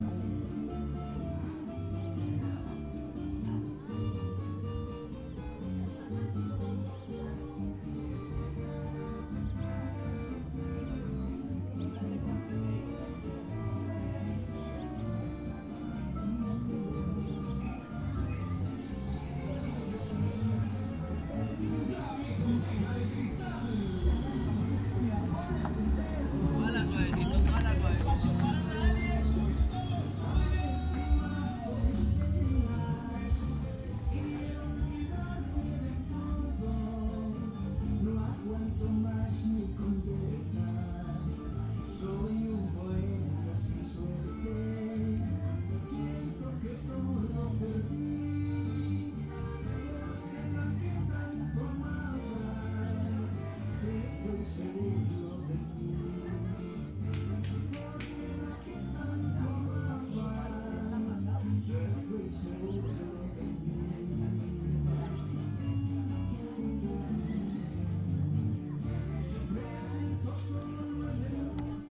Trujillo-cantinas a las afueras.mp3
Grabación de sonidos entre calles de cantinas en Trujillo, Valle del Cauca.